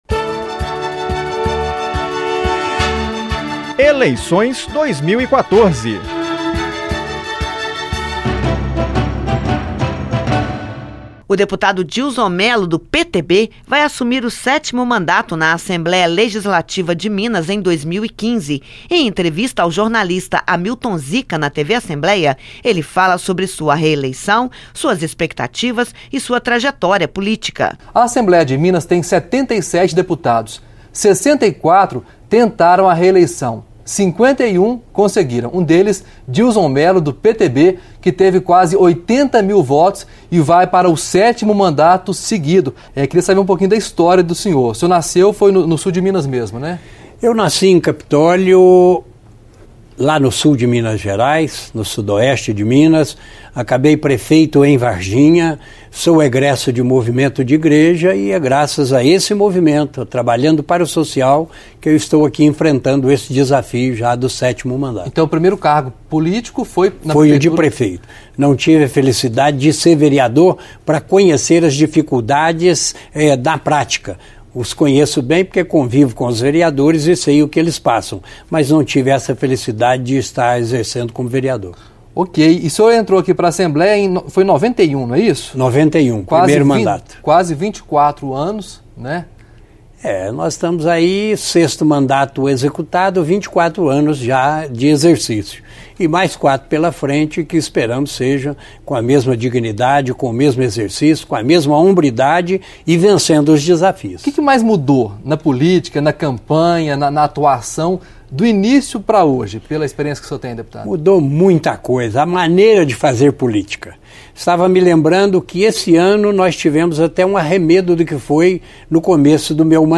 Eleições 2014 - Deputado Dilzon Melo (PTB) fala sobre os desafios do 7º mandato
Entrevistas